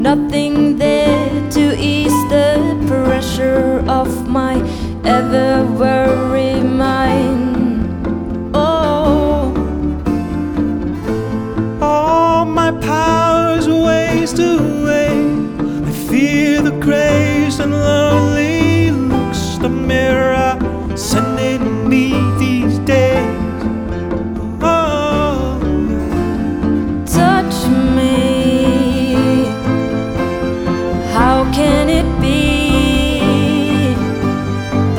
Жанр: Поп музыка
Pop